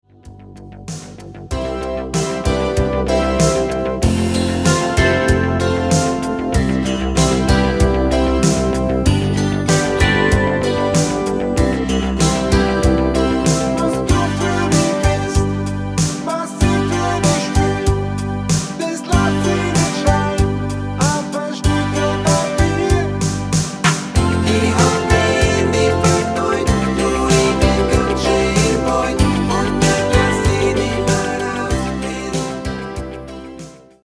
Mit Backing Vocals